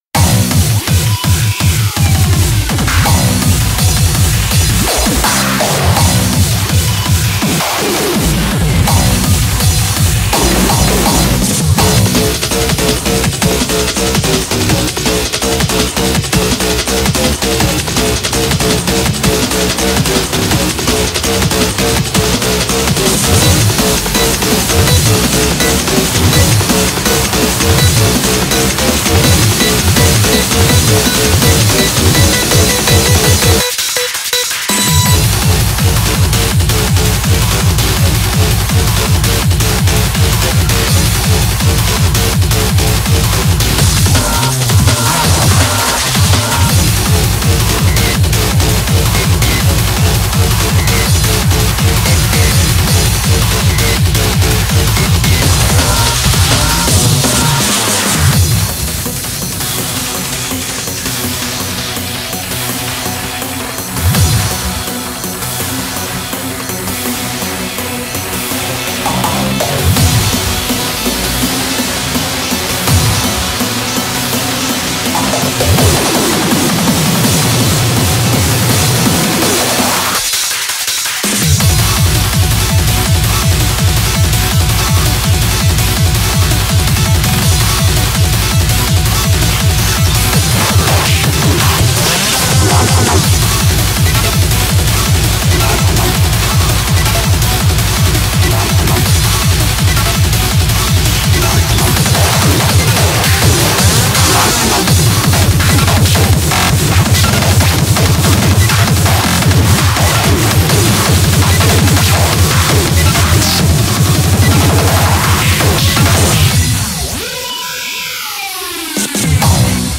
BPM165
Comments[SCHRANZ]